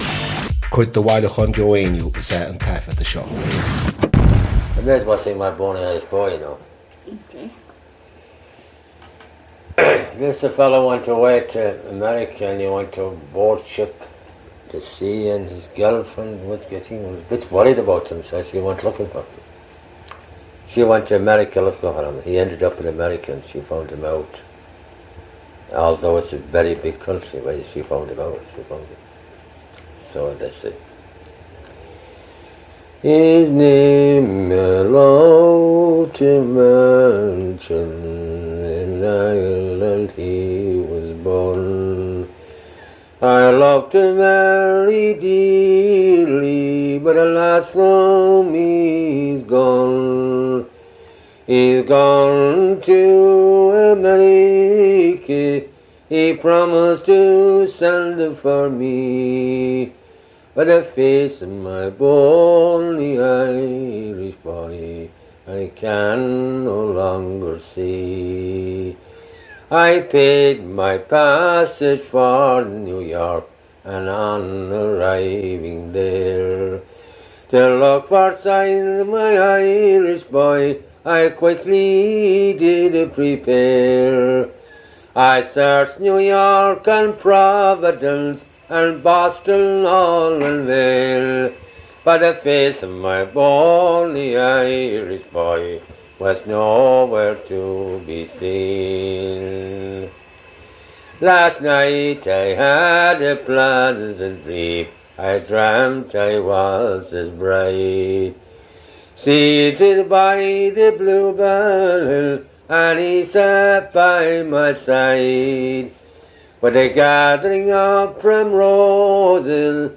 • Catagóir (Category): Song.
• Ainm an té a thug (Name of Informant): Joe Heaney.
• Suíomh an taifeadta (Recording Location): Bay Ridge, Brooklyn, New York, United States of America.
• Ocáid an taifeadta (Recording Occasion): Private.